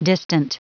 Prononciation du mot : distant